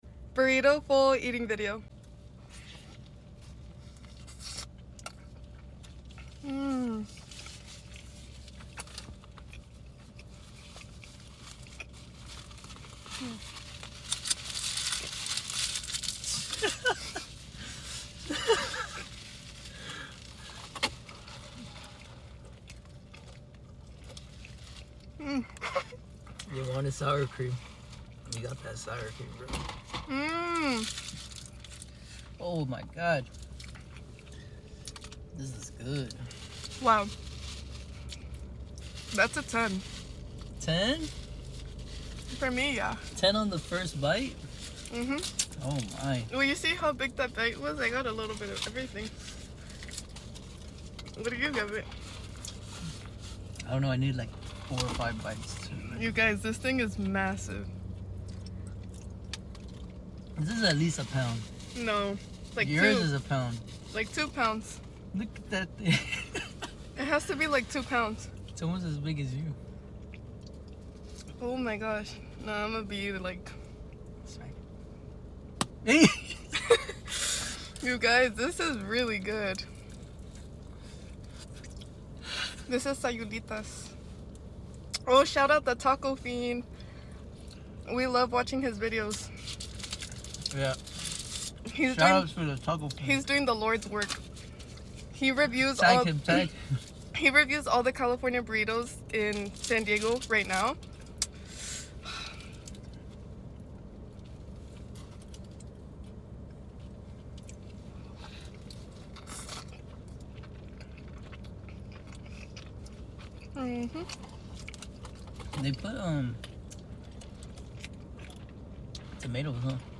BURRITO FULL EATING VIDEO!🌯❤ OMGG sound effects free download